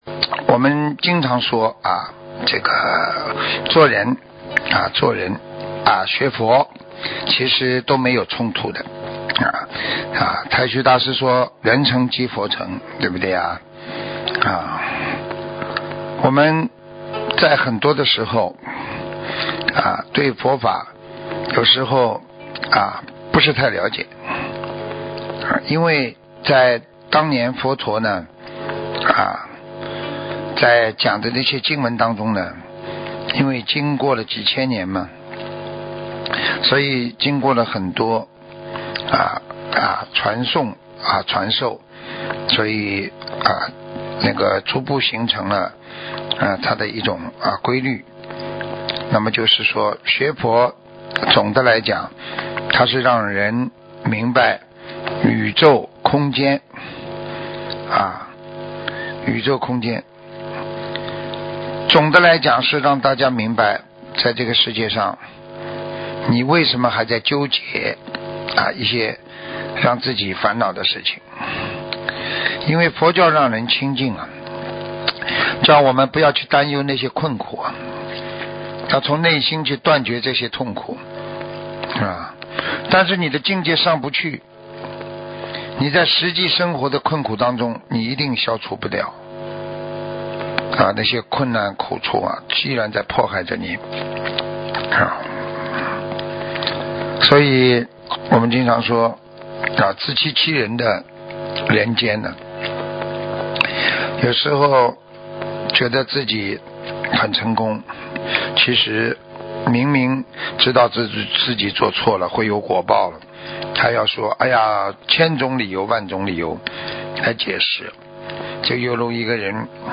*我们的睡前一听就是师父的广播讲座栏目，合集在此*